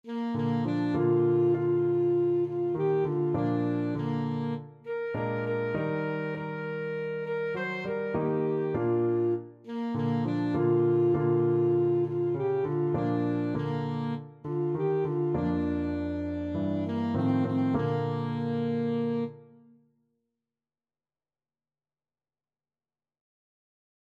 Christian
Alto Saxophone
4/4 (View more 4/4 Music)
Bb4-C6